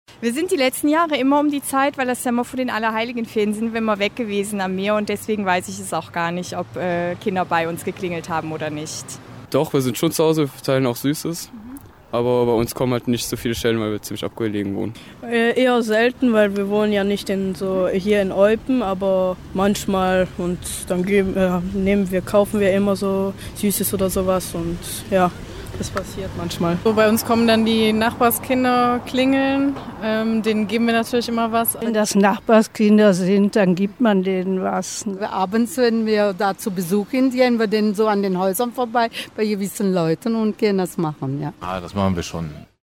Umfrage-Halloween-Süßes-2017.mp3